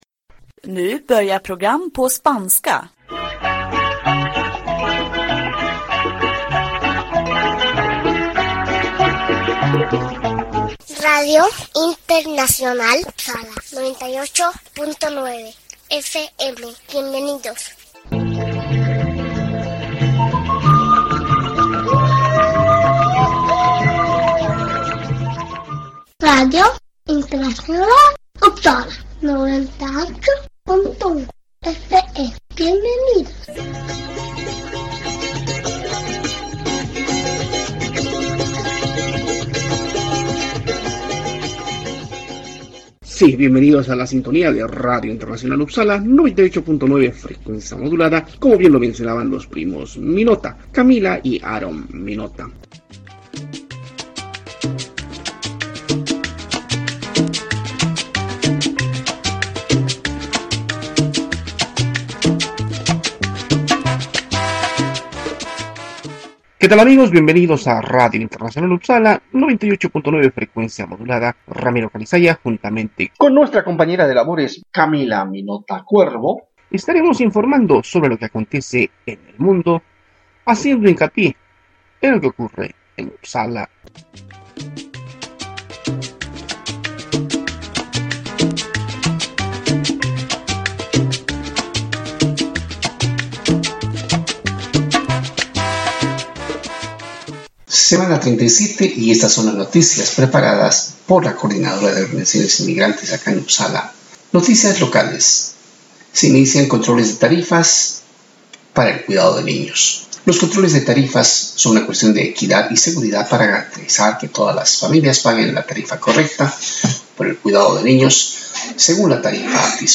Sí, radio de cercanías en Uppsala se emite domingo a domingo a horas 18:30.